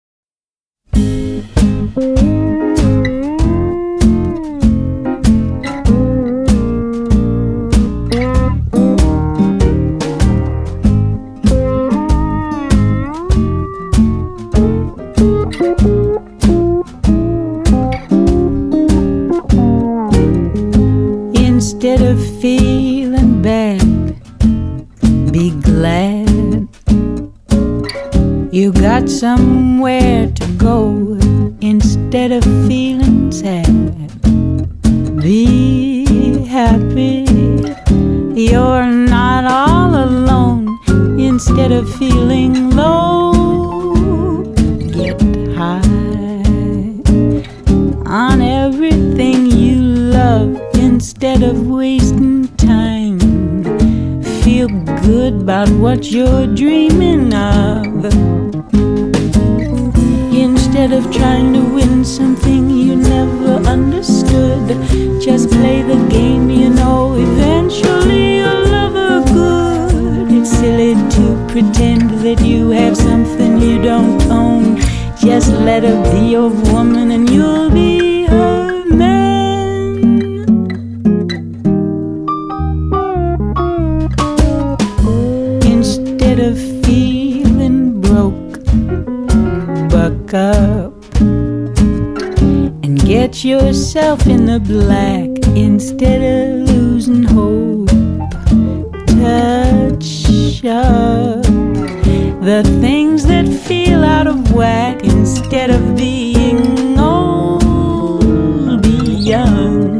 爵士及藍調 (563)